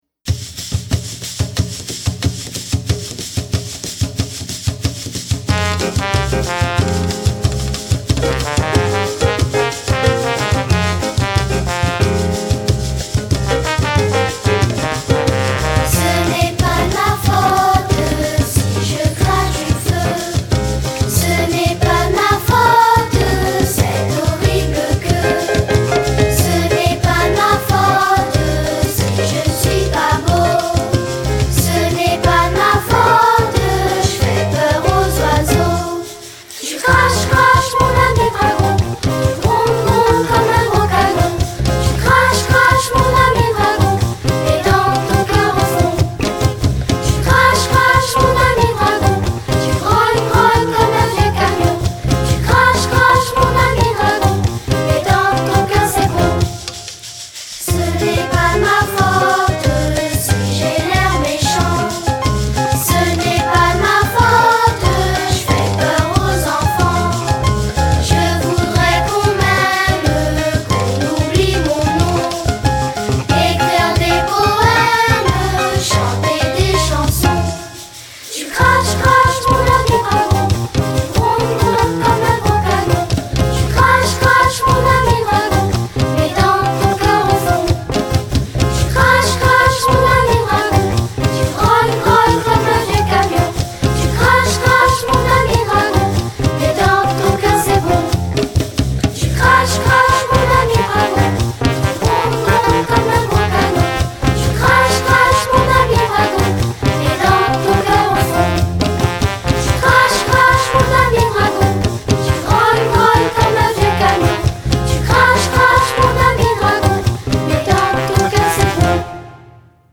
la chanson avec les paroles